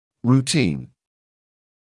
[ruː’tiːn][руː’тиːн]обычный, регулярный; стандартный